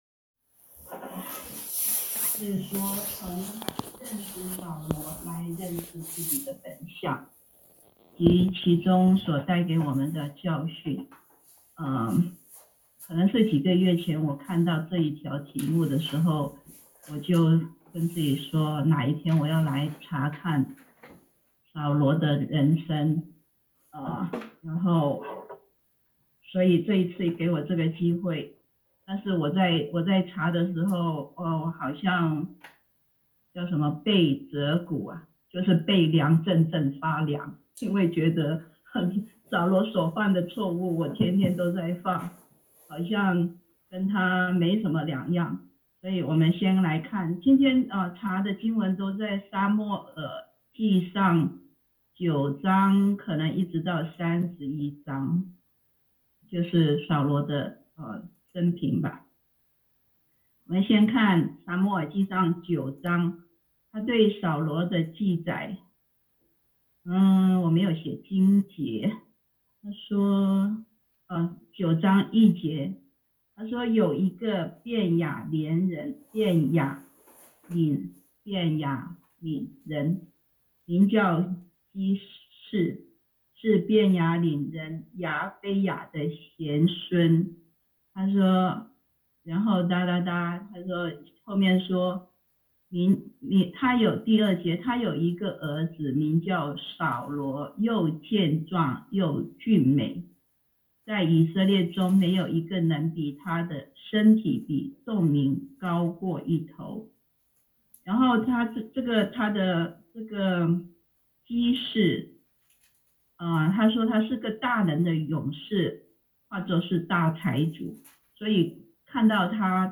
講道下載